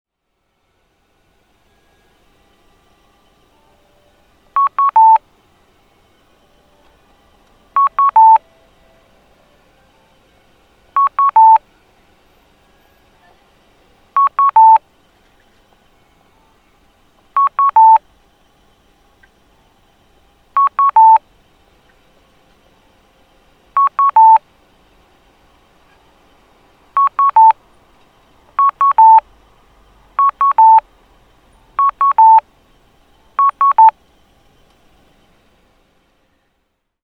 検察庁前(大分県大分市)の音響信号を紹介しています。